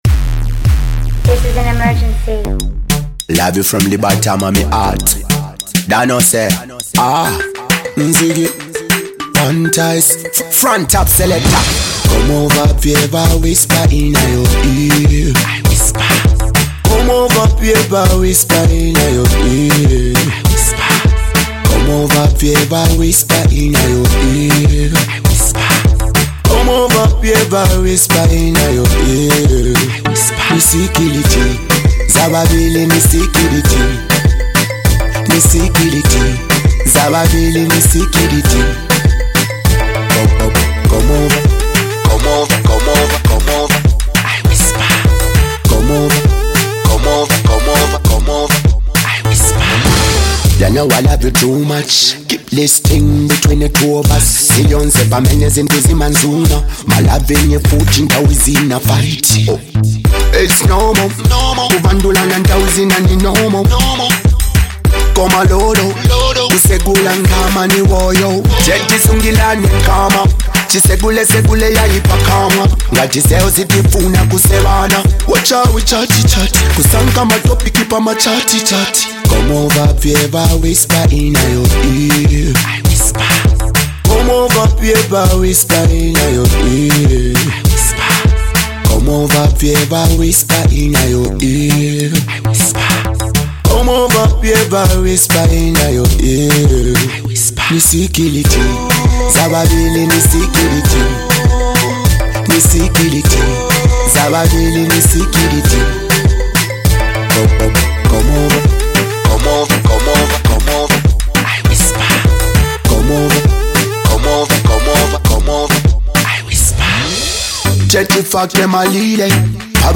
nice and groovy Ragga Jam